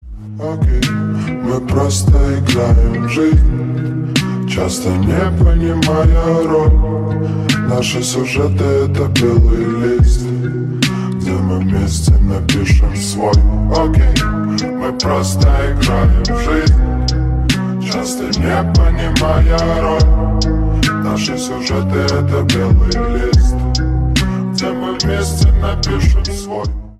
бесплатный рингтон в виде самого яркого фрагмента из песни
Поп Музыка
грустные